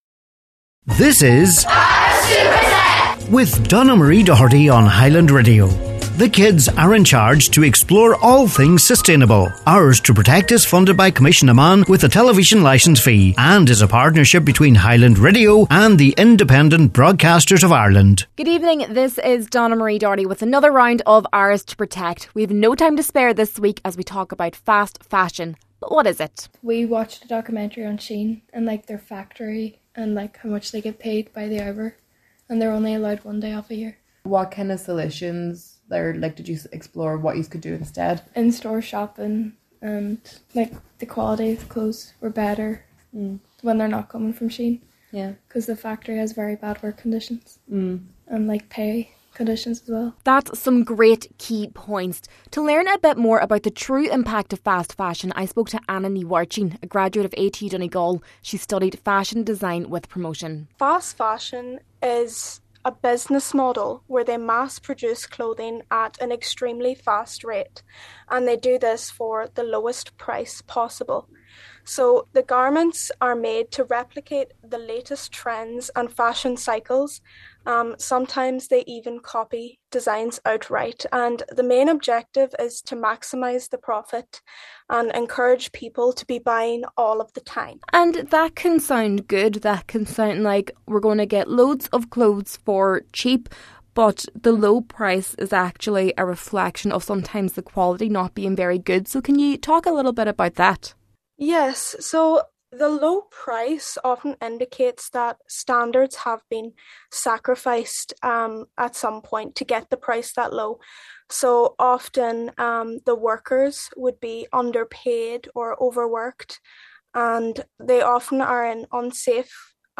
We’re diving deep into the future of our planet, and the brilliant young minds of Donegal will be leading the conversation!